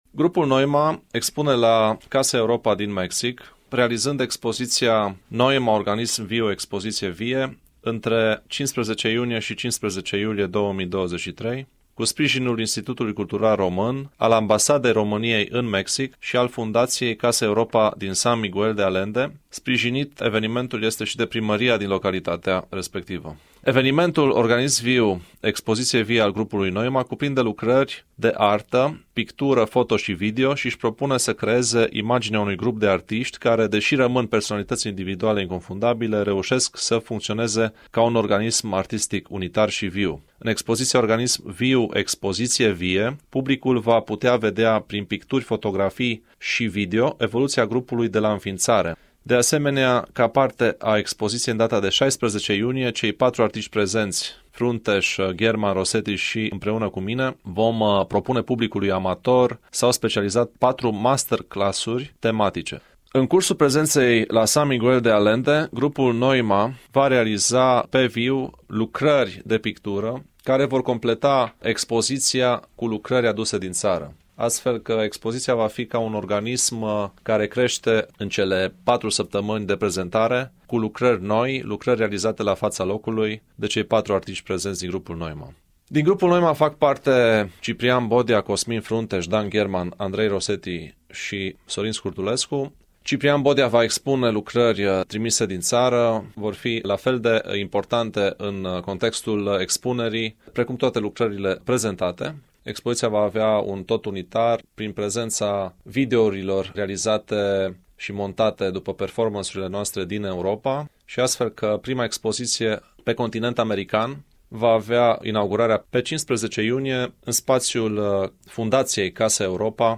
interviu Radio Timișoara